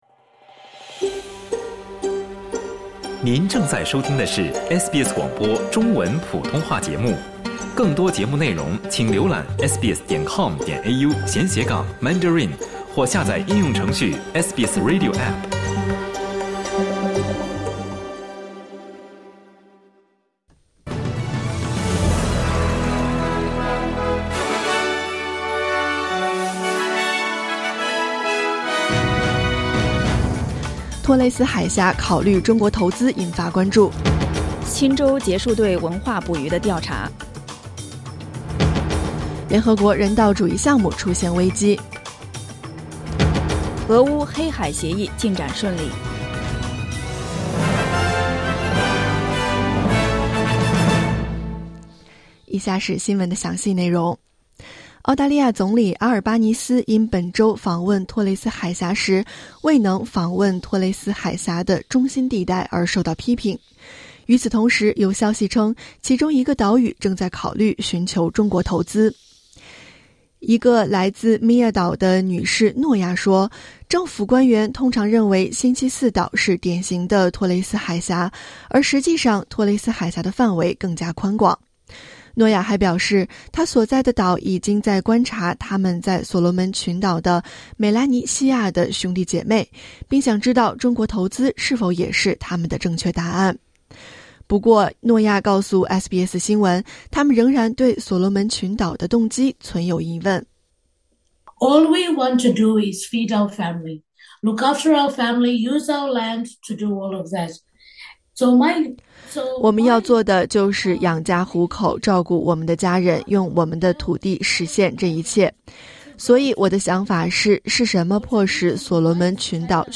SBS早新闻（2022年8月20日）